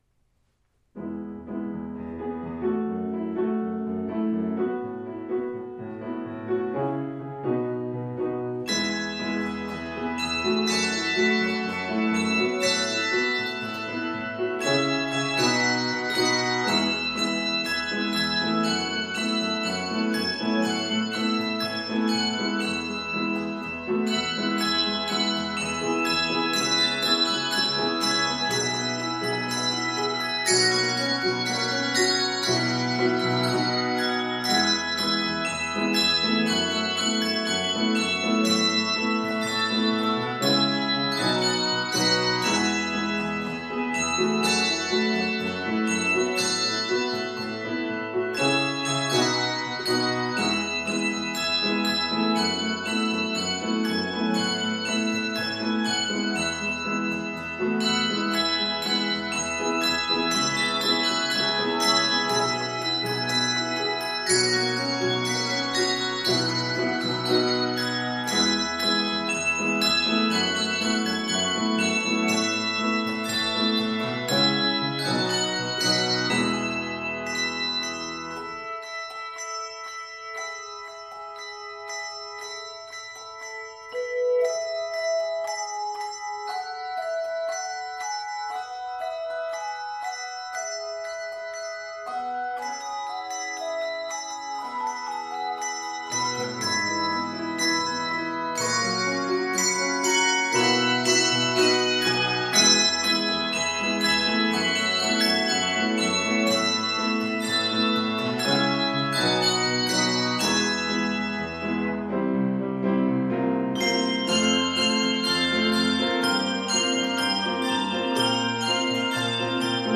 Keys: E Major and F Major Published by